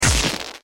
spark.mp3